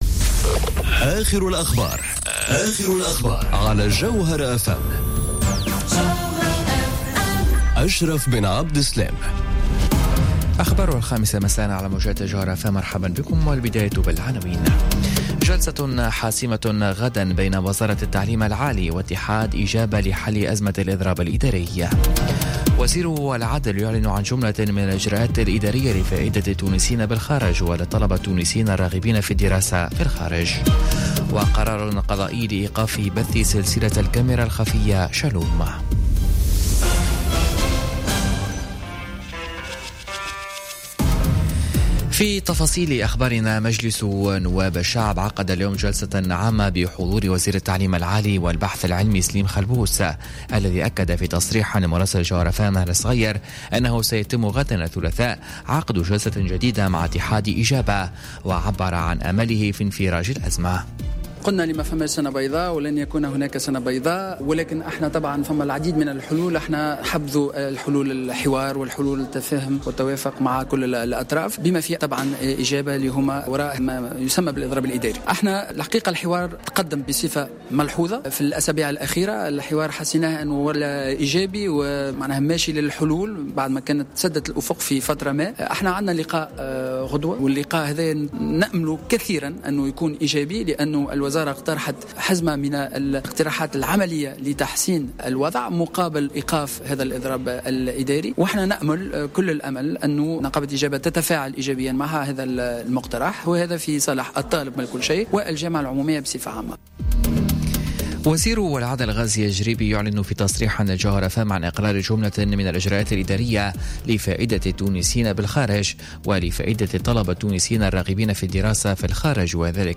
نشرة أخبار الخامسة مساء ليوم الاثنين 28 ماي 2018